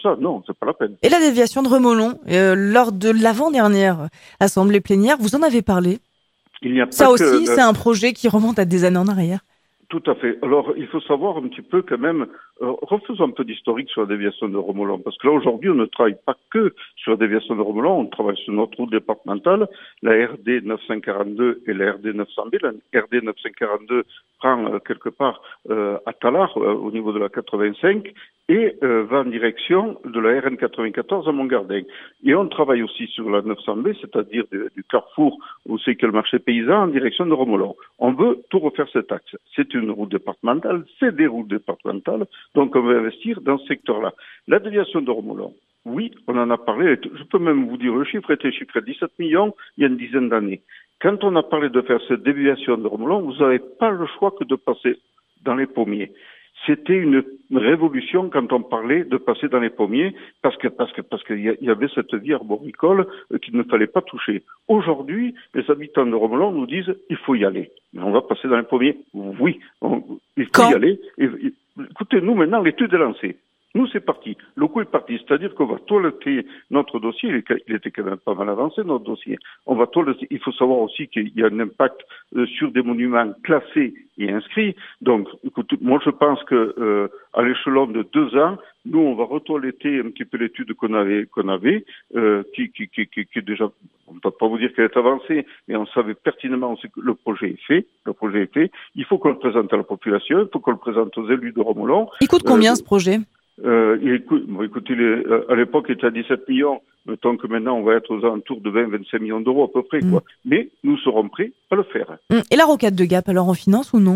Sur la radio Alpes 1, Marcel Cannat vice-président du conseil départemental des Hautes-Alpes en charge des infrastructures routières annonce la réalisation prochaine de la déviation de Remollon.
Écoutez la partie de l’interview concernant la déviation du village.